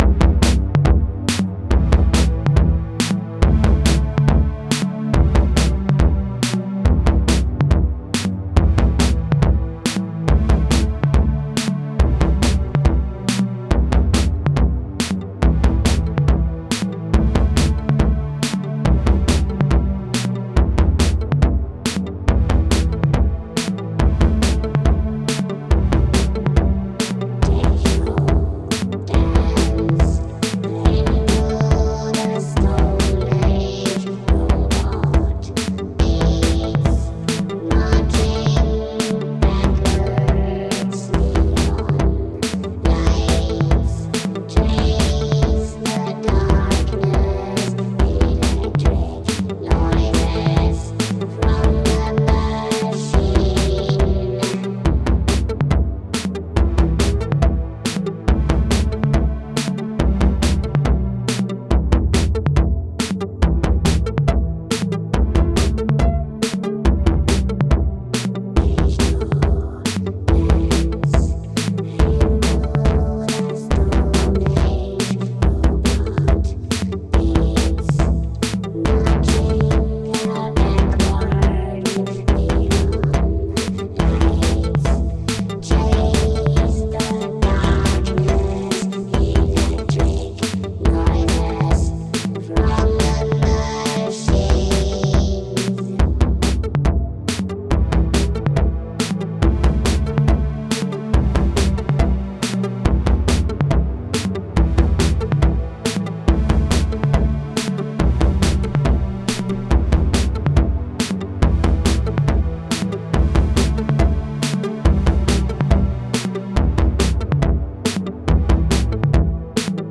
a heavy dose of melodic, vocoded vocals
Dreamy, lush, and full of character.